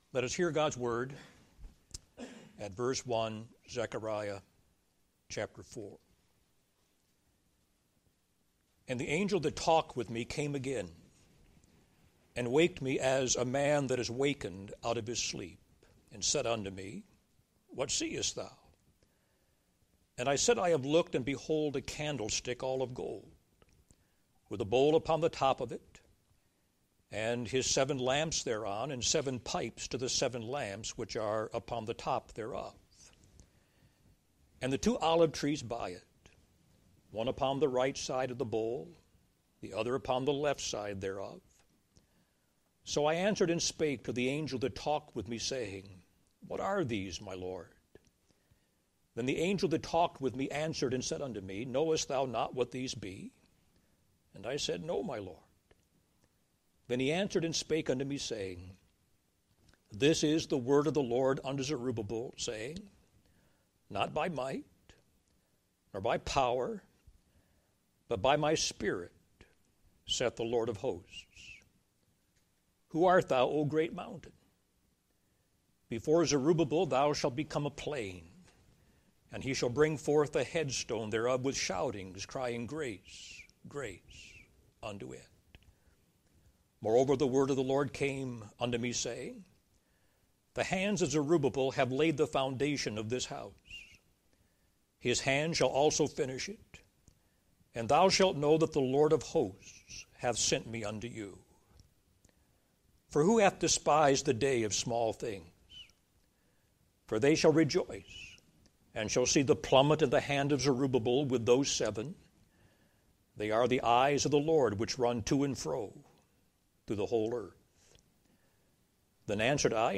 Office Bearer's Conference